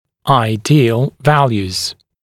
[aɪ’diːəl ‘væljuːz][ай’ди:эл ‘вэлйу:з]идеальные значения